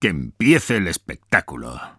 Escucha las voces de algunos de los personajes antes de ser tratadas en post-producción con música y efectos sonoros.